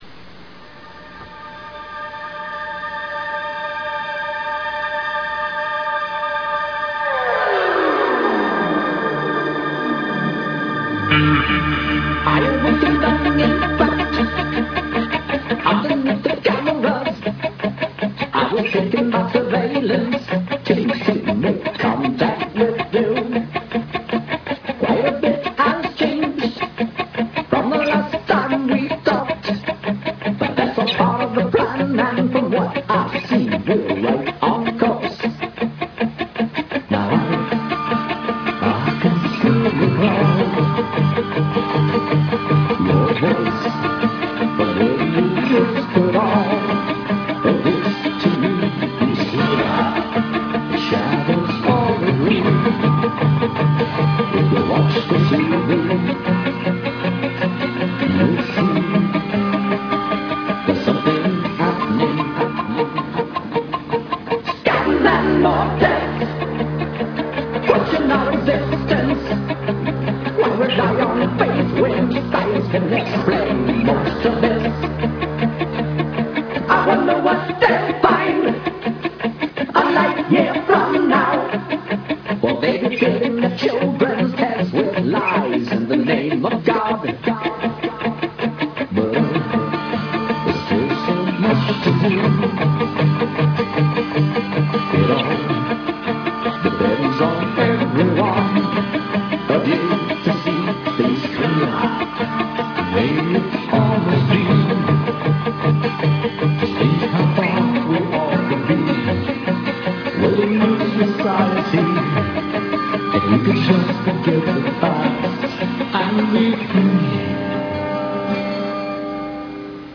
Unveröffentlichter Demo-Song.